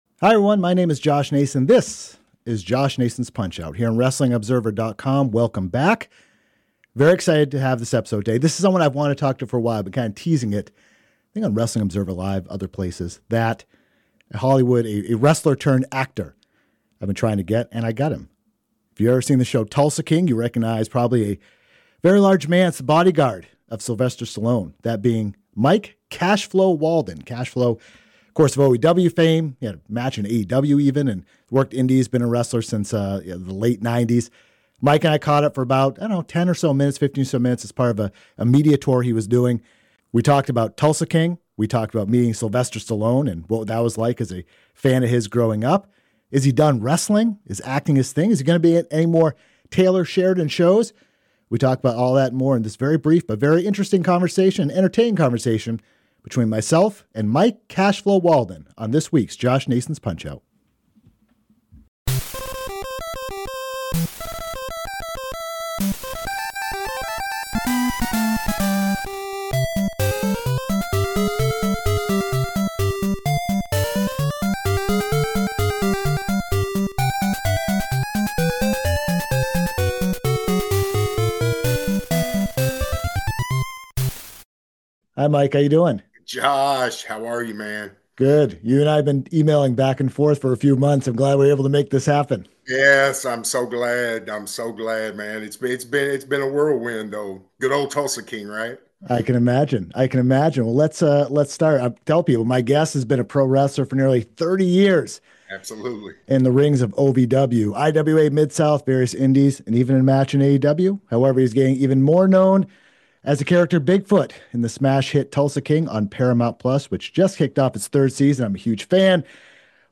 Here’s the image talked about during the interview.